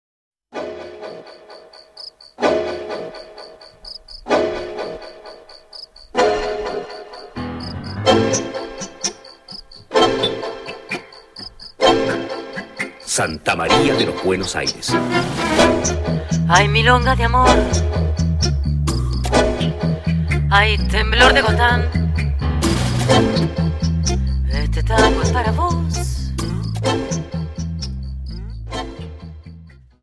Dance: Tango 32 Song